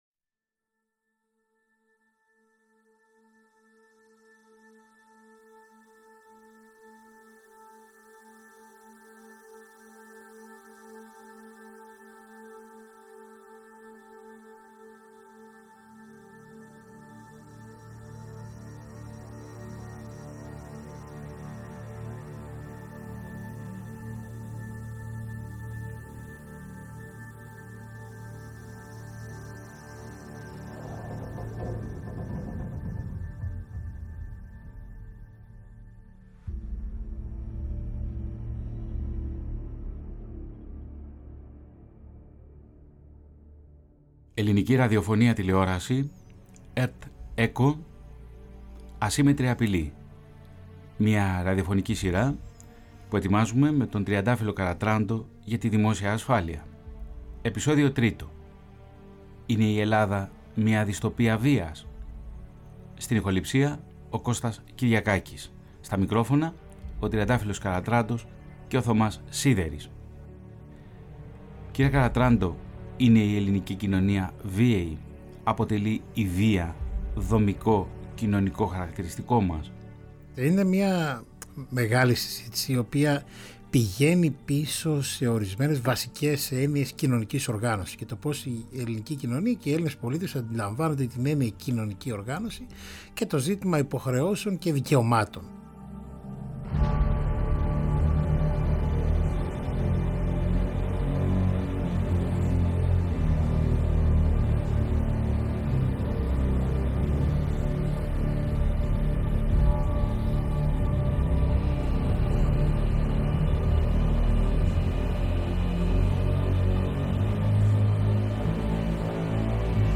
υποδέχεται στα στούντιο της ΕΡΤ